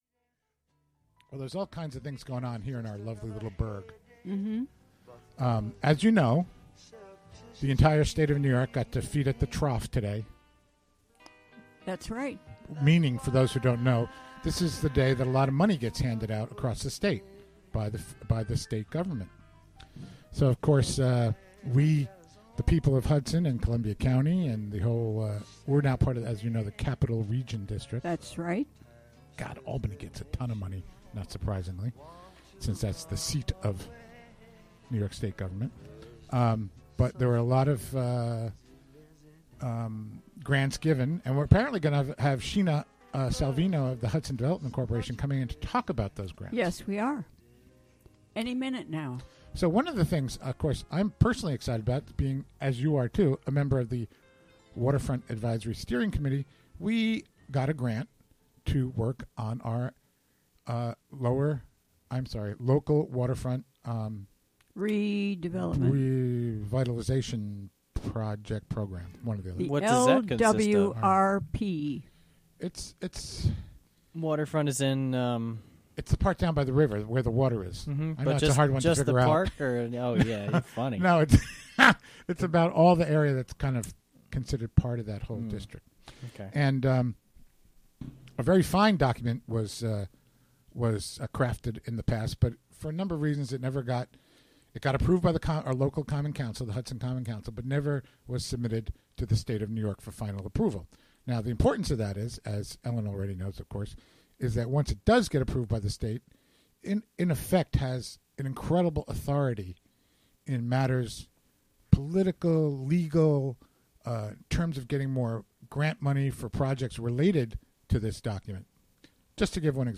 Recorded live during the WGXC Afternoon Show, Thu., Dec. 8.